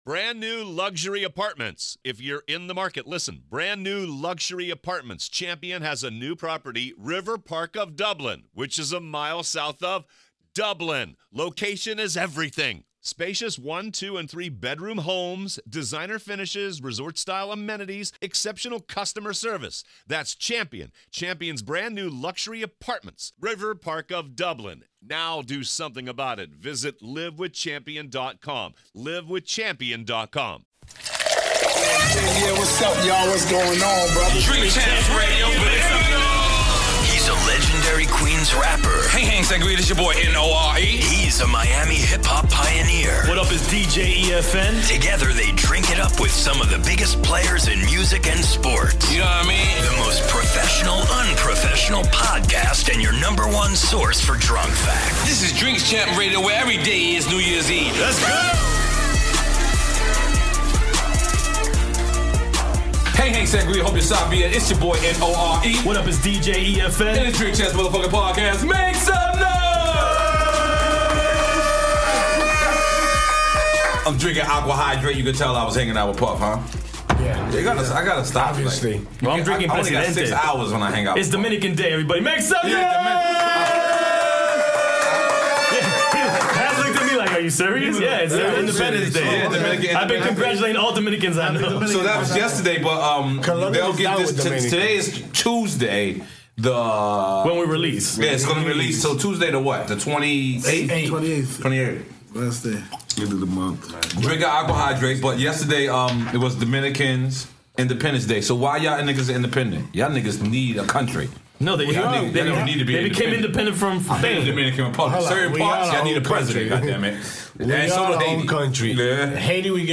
N.O.R.E. and DJ EFN are the Drink Champs. In this episode the guys discuss the Swizz Beatz vs Just Blaze battle, Remy Ma vs Nicki Minaj and other topics. Special call ins by Fat Joe, Puff Daddy, Swizz Beatz, Just Blaze, Consequence and round table discussion with the D.C. family.